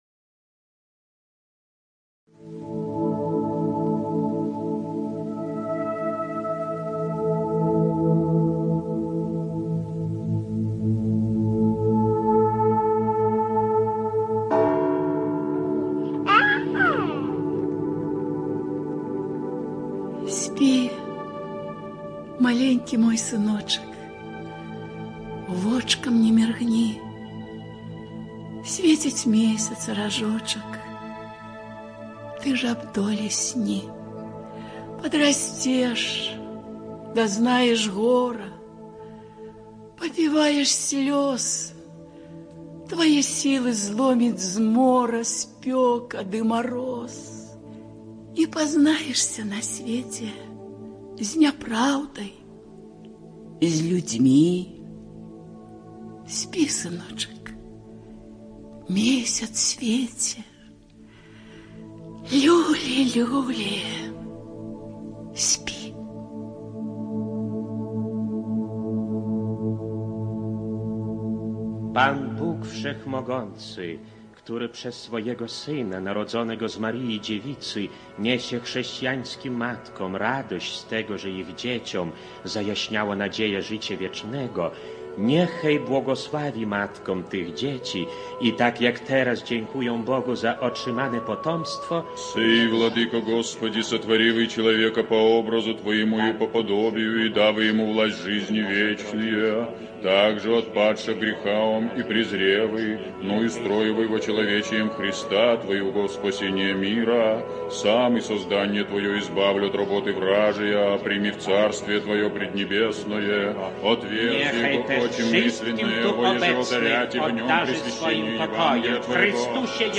ЖанрРадиоспектакли на белорусском языке